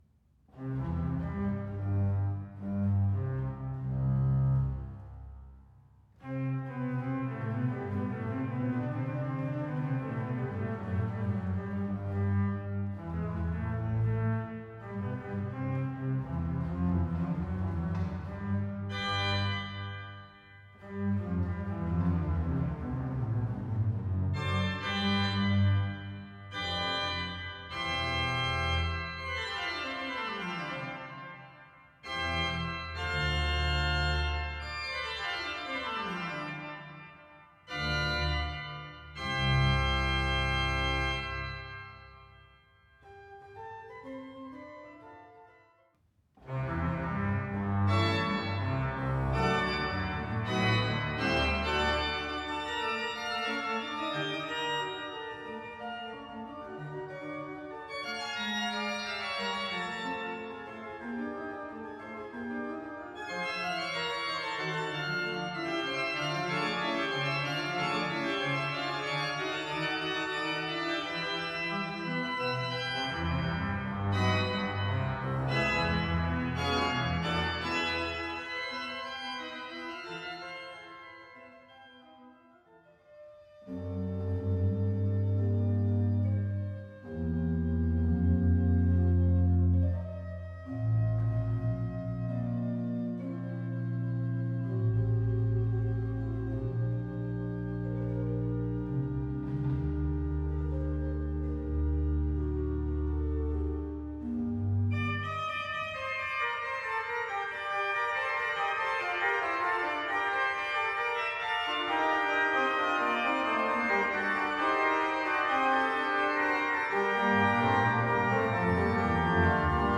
Orgel - Lukasgemeinde Lampertheim
Mit 43 klingenden Registern auf drei Manualen und Pedal zeigt sich die Orgel äußerst vielseitig und erlaubt eine große musikalische Vielfältigkeit, wobei mit zwei Schwellwerken und Walze den deutsch-romantischen Intentionen im Besonderen Rechnung getragen wurde.
demoORGEL.mp3